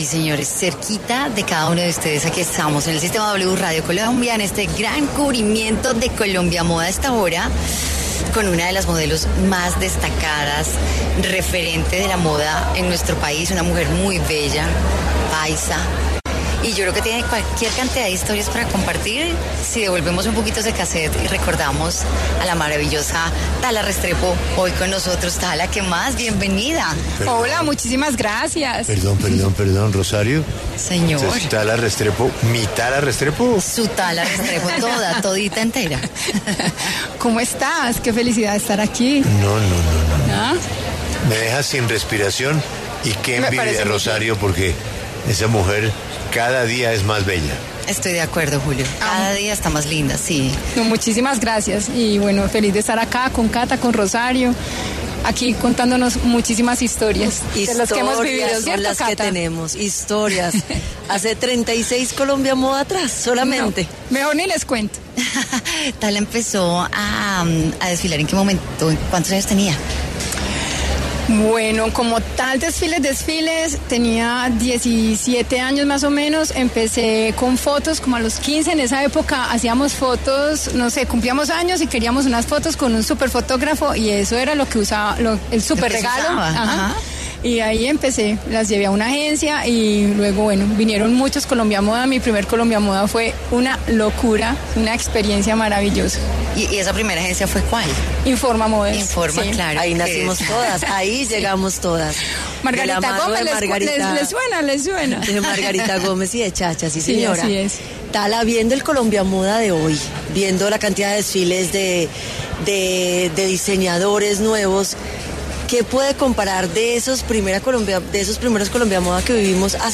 habló de su experiencia en entrevista con W Radio.